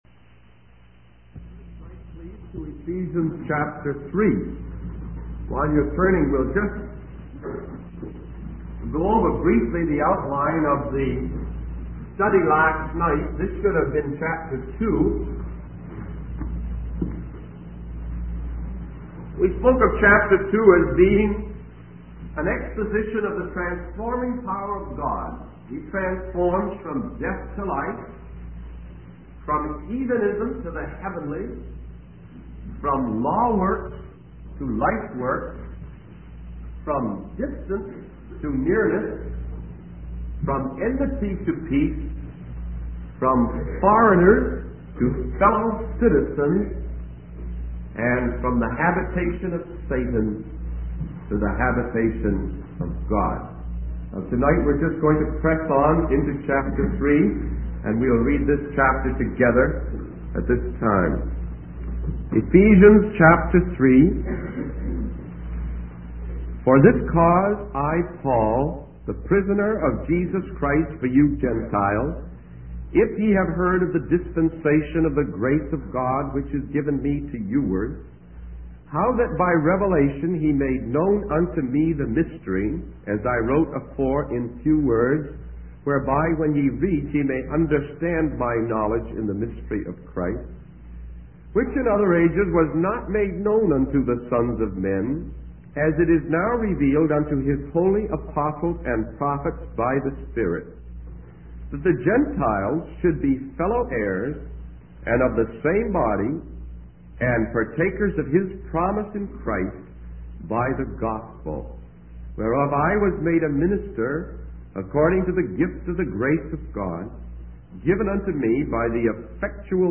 In this sermon, the speaker begins by explaining the structure of the chapter being discussed.